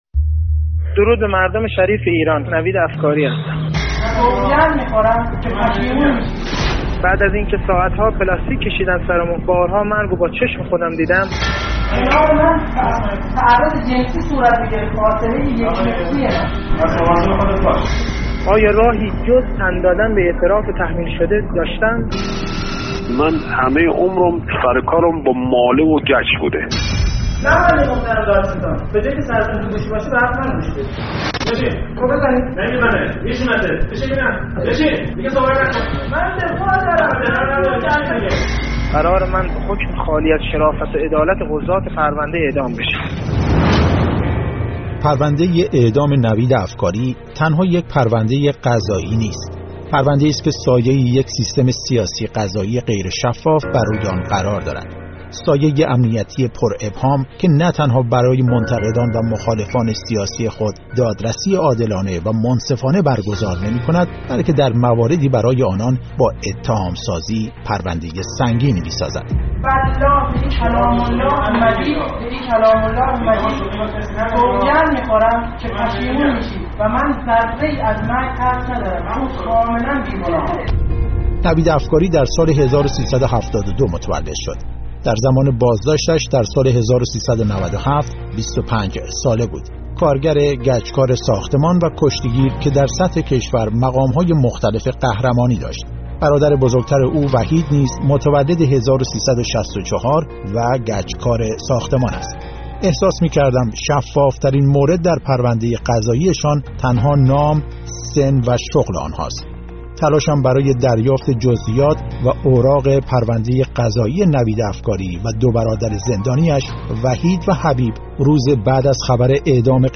مستند رادیویی: «پرونده نوید افکاری»